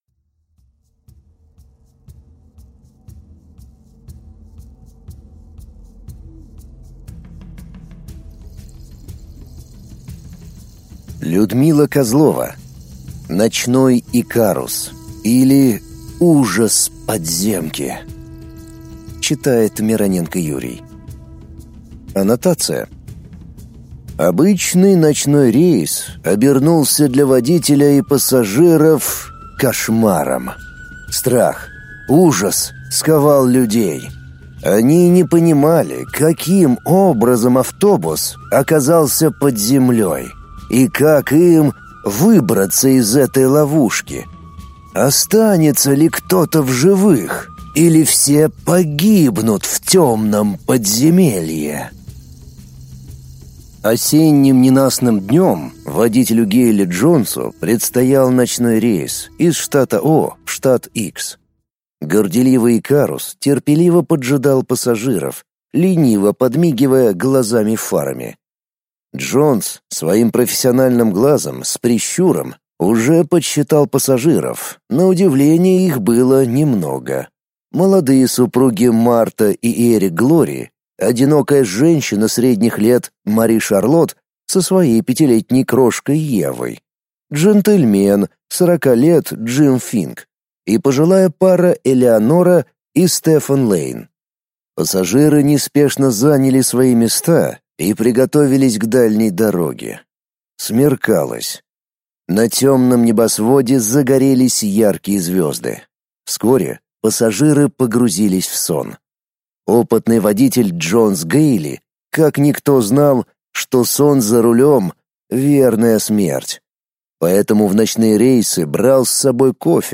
Аудиокнига Ночной икарус, или Ужас подземки | Библиотека аудиокниг